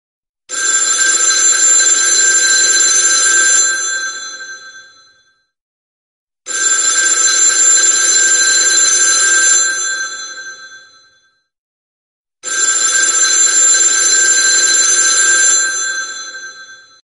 Category Sound Effects